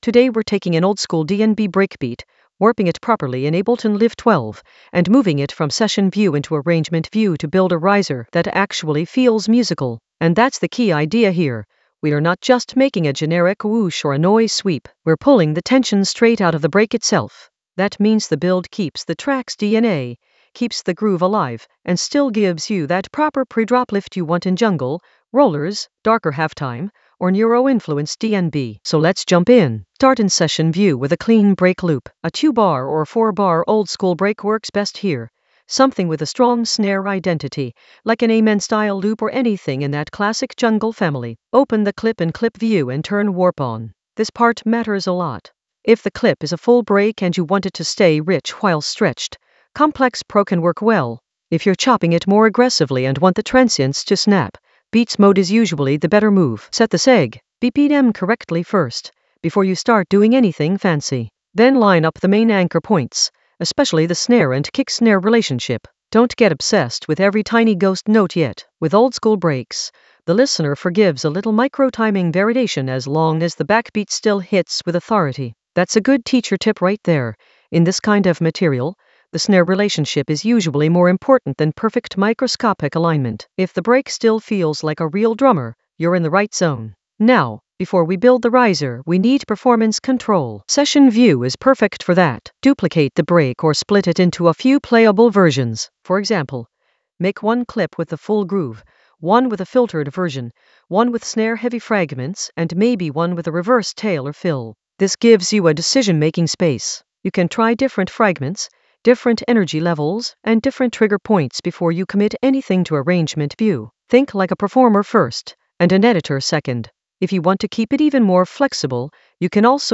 An AI-generated intermediate Ableton lesson focused on Warp oldskool DnB breakbeat using Session View to Arrangement View in Ableton Live 12 in the Risers area of drum and bass production.
Narrated lesson audio
The voice track includes the tutorial plus extra teacher commentary.